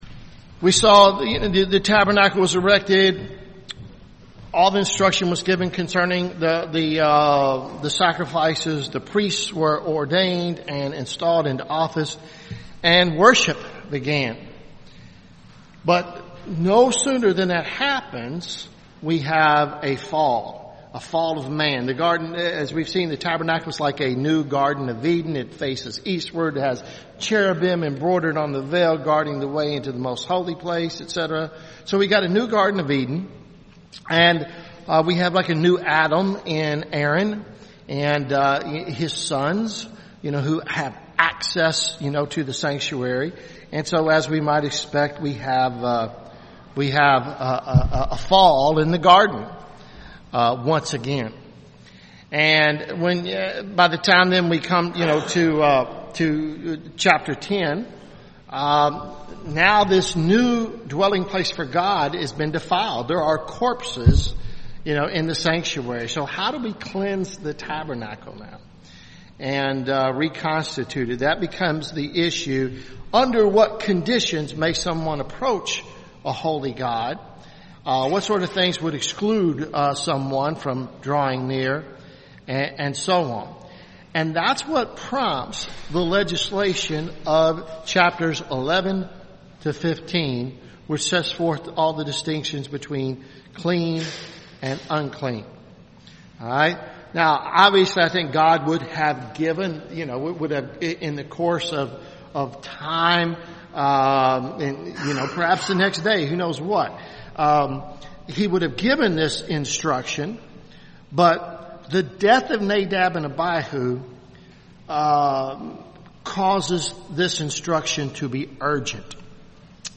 Leviticus Part 17 Leviticus Sunday school series